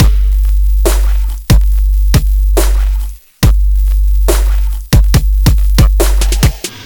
Drums.wav